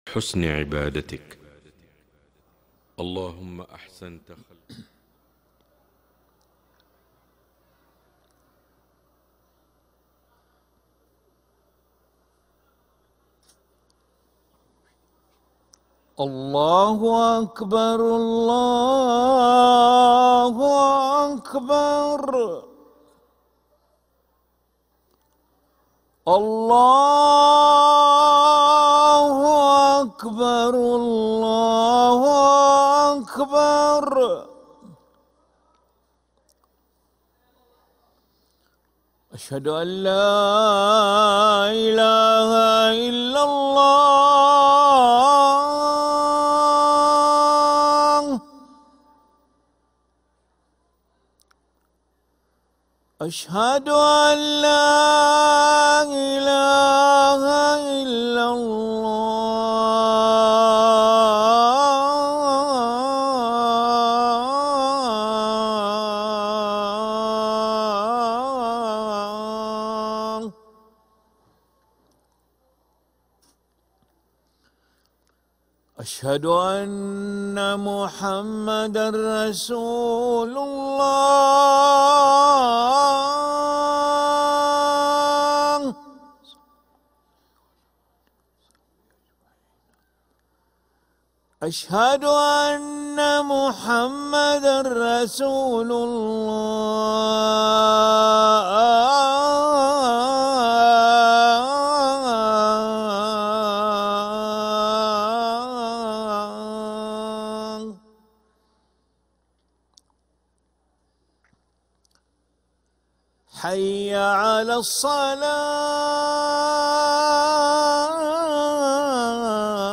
أذان العشاء للمؤذن علي ملا الأحد 22 جمادى الأولى 1446هـ > ١٤٤٦ 🕋 > ركن الأذان 🕋 > المزيد - تلاوات الحرمين